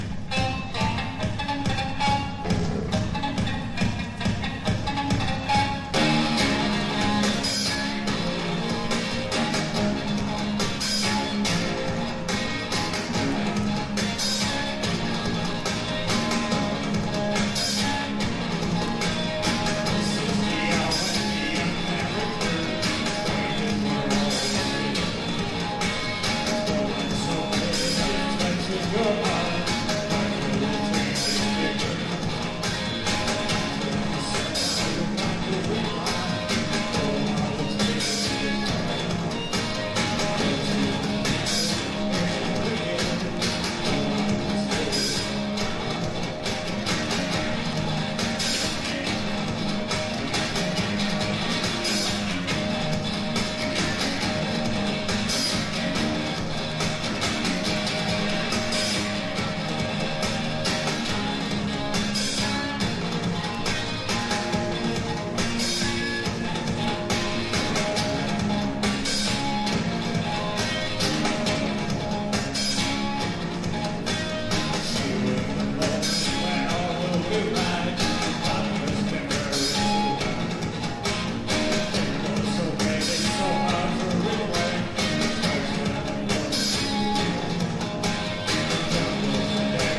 とにかく鋭く緊張感溢れるライブが素晴らしい。
# NEW WAVE# POST PUNK# 80’s ROCK / POPS
所によりノイズありますが、リスニング用としては問題く、中古盤として標準的なコンディション。